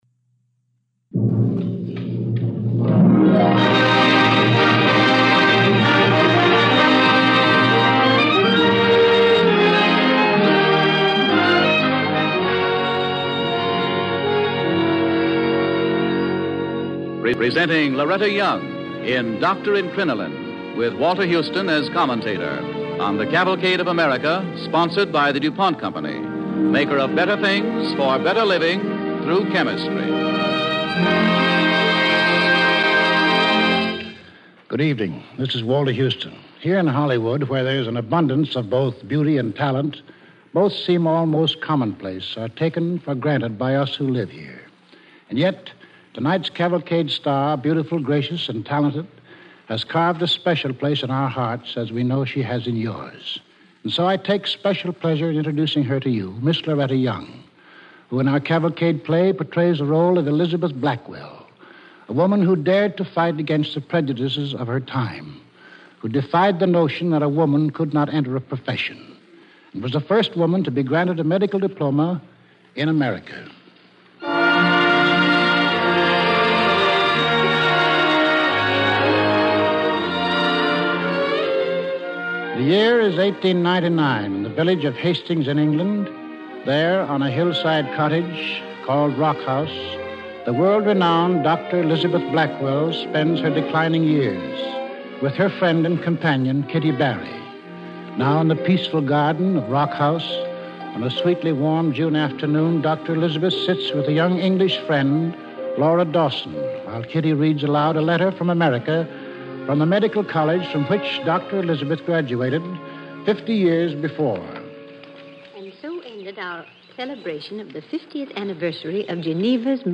starring Loretta Young with host Walter Houston
Cavalcade of America Radio Program